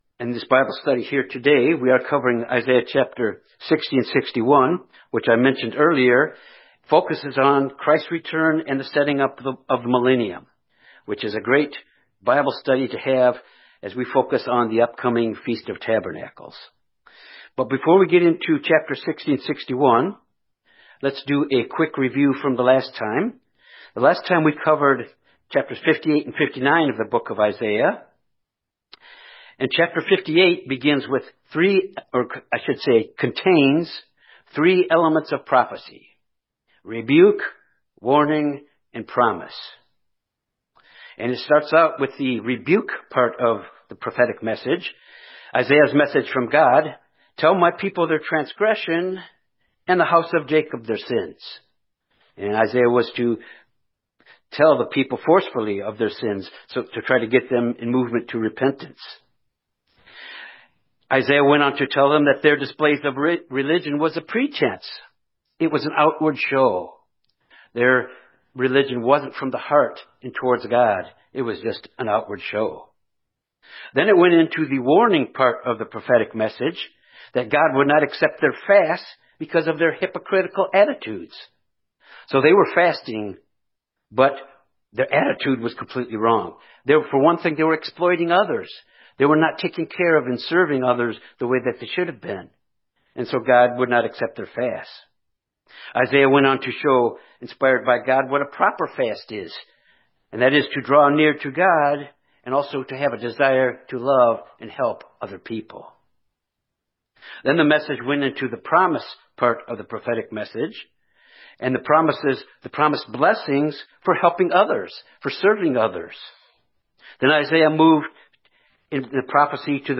Given in Little Rock, AR Memphis, TN Jonesboro, AR
Bible study Isaiah Studying the bible?